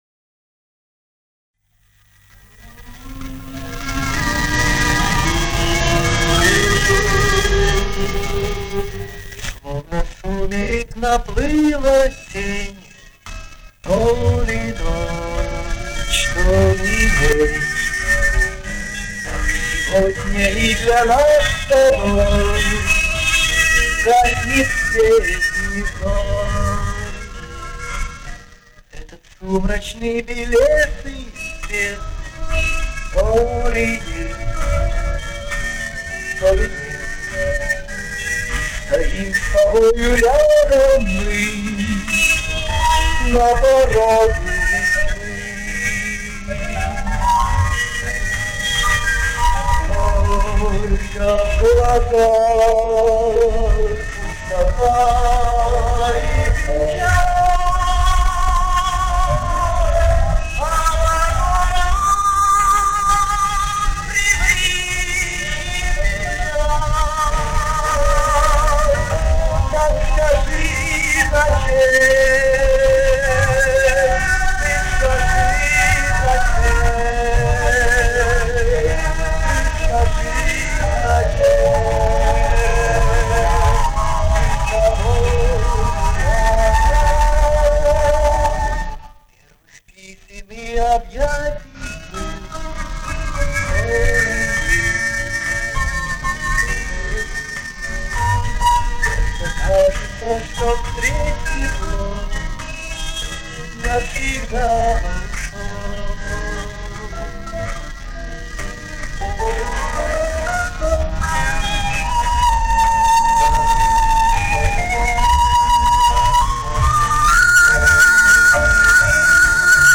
Первое исполнение. С этой же ленты ORWO.
Это с мастер ленты ORWO, еще ацетатной.